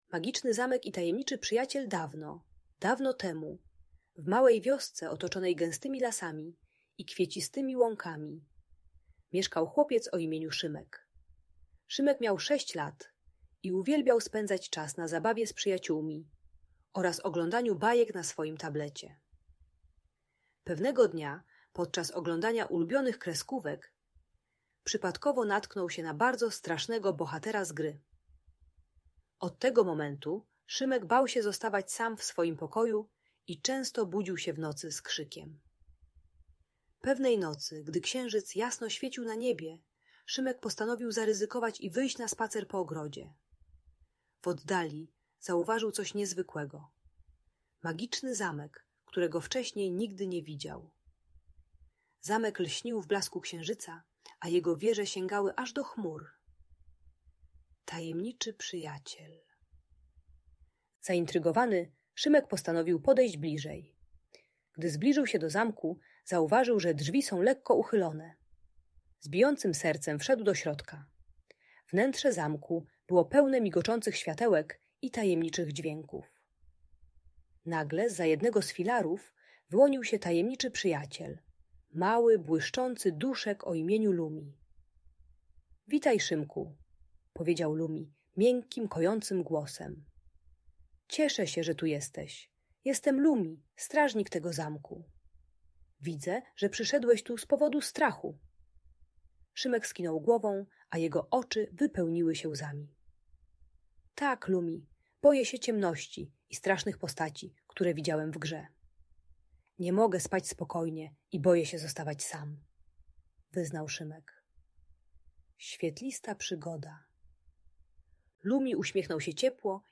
Magiczny Zamek i Tajemniczy Przyjaciel - Audiobajka